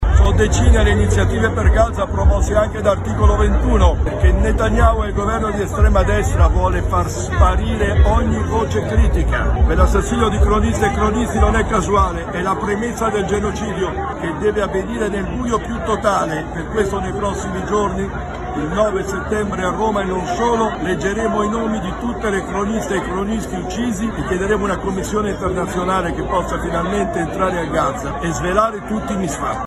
Leggere i nomi dei giornalisti uccisi finora a Gaza, ad alta voce, in piazza: è l’iniziativa di Articolo 21 e Ordine dei Giornalisti Lazio con molte altre associazioni aderisce il 9 settembre a Roma. Ascoltiamo il presidente di Articolo 21 Beppe Giulietti.